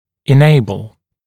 [ɪ’neɪbl] [en-][и’нэйбл] [эн-]делать возможным, давать возможность